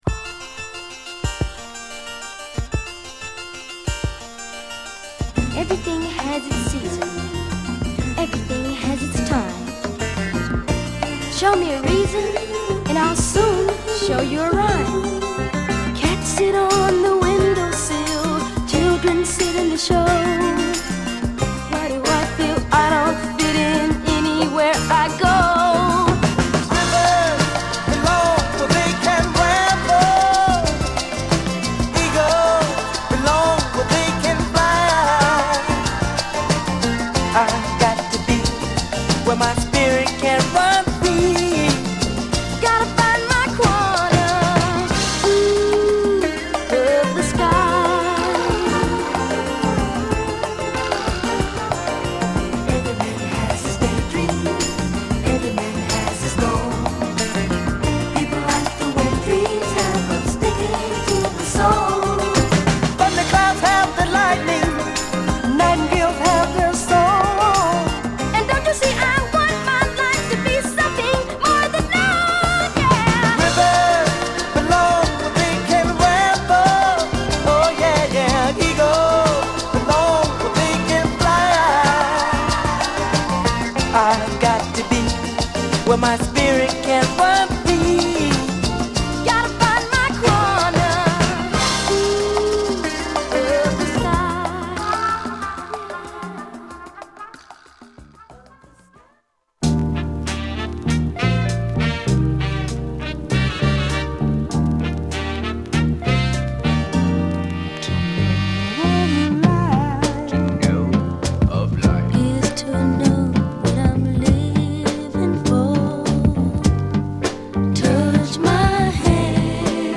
グルーヴィーなミディアムトラック
爽やかさとメロウネスが混在したグッド・ミディアム